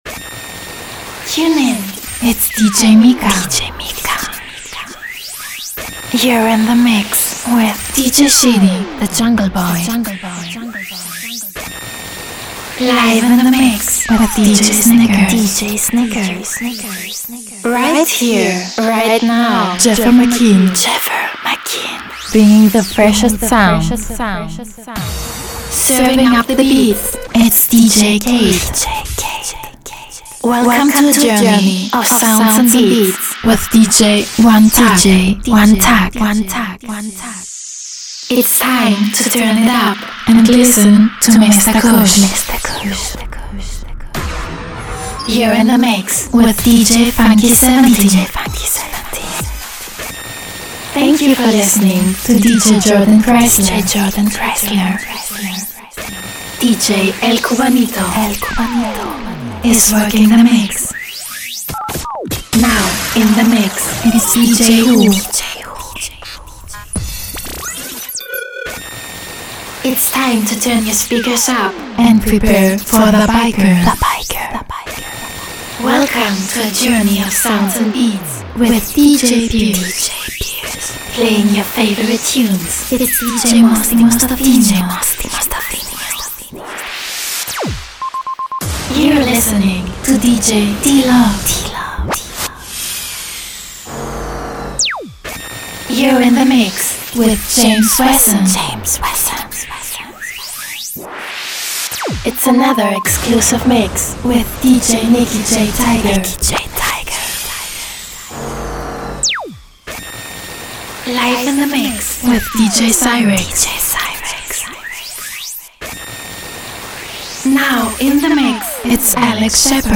Sexy Female DJ Drops
Dj Intro
FEMALE VOICE